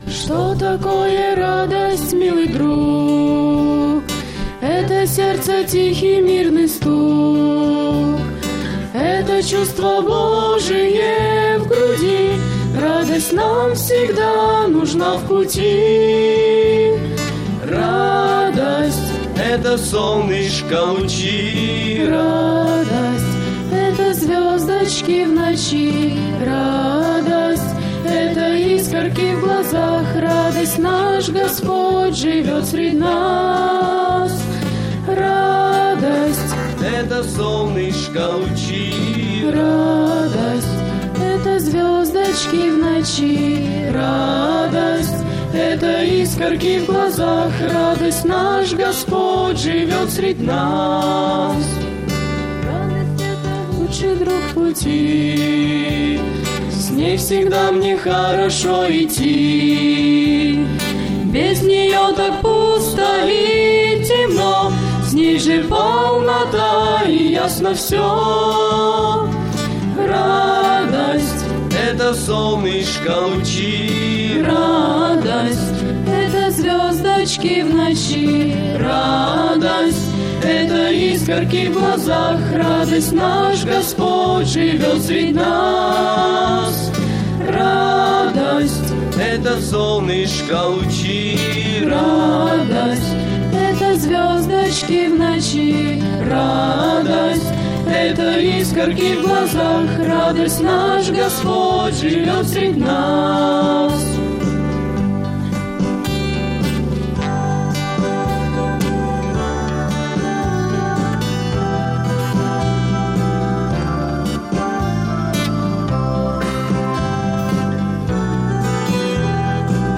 Богослужение 28.03.2010 (Вербное воскресение) mp3 видео фото
Что такое радость милый друг - Фимиам (Пение)